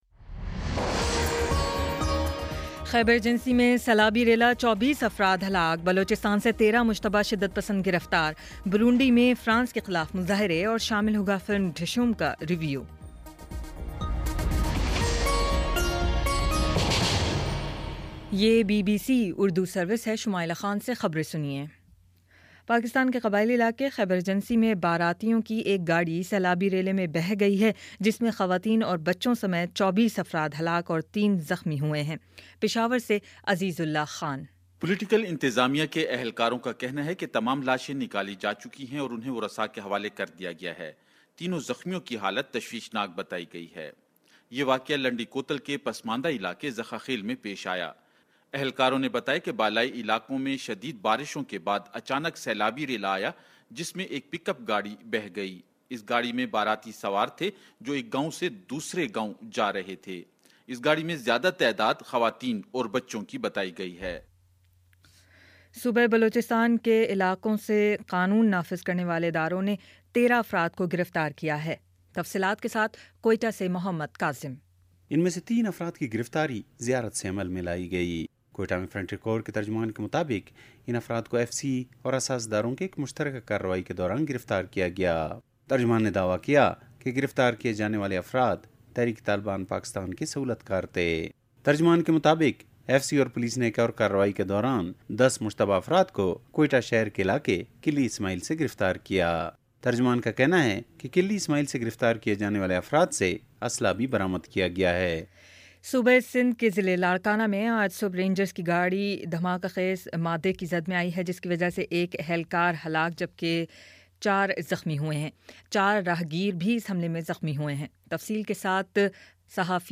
جولائی 30 : شام پانچ بجے کا نیوز بُلیٹن